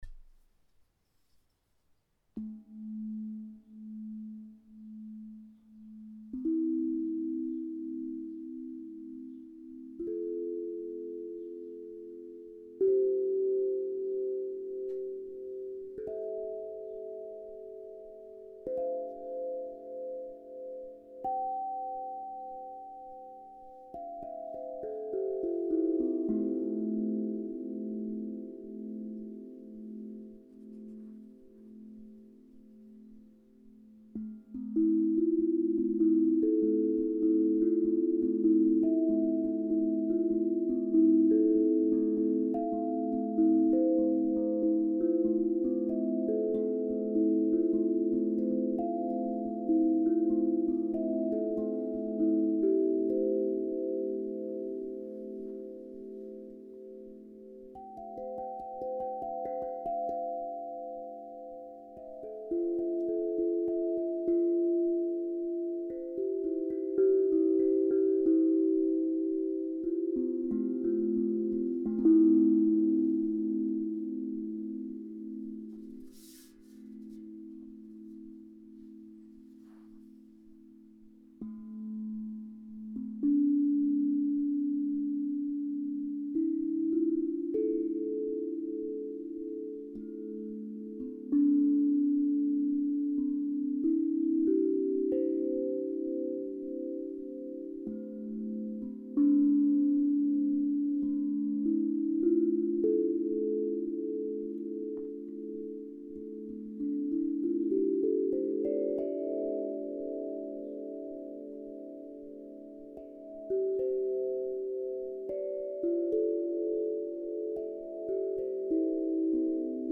Tank drum Douceur en double La 432 hz 21 notes - Osb Drum
Il a deux faces jouables, la première est un La Heptatonique modifié de 11 notes, très facile à jouer et tout doux. La seconde face est un arpège de La mineur 7 de 10 notes, plus profonde et polyvalente.